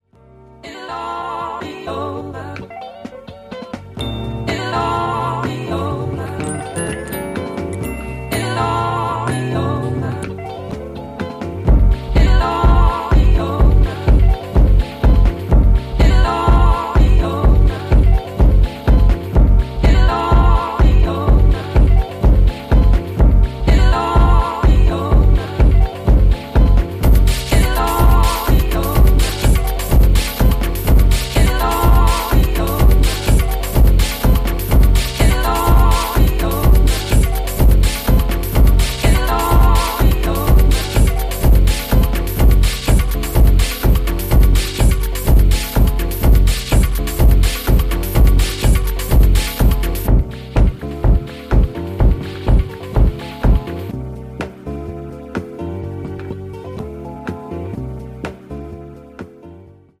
lo-fi sound
falsetto voices and xylophone pings